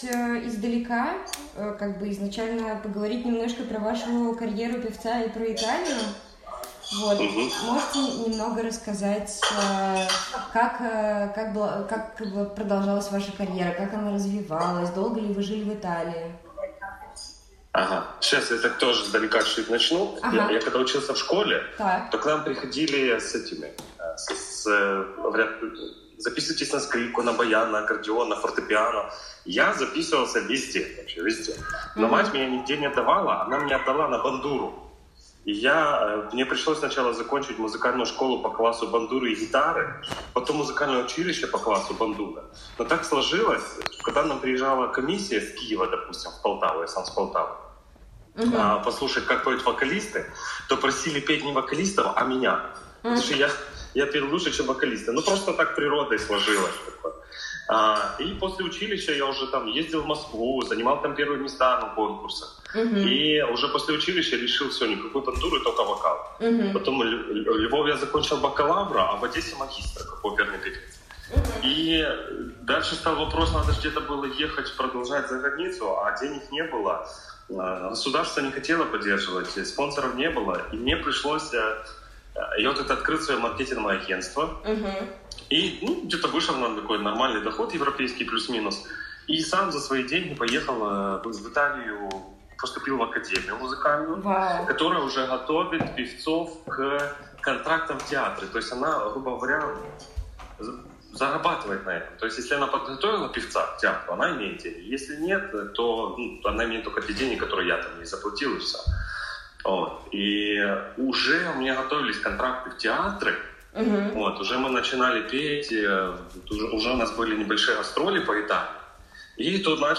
Я отключаюсь и темнота. Никаких туннелей, ангелов, разговоров — личные свидетельства войны в Украине, архив «Службы поддержки»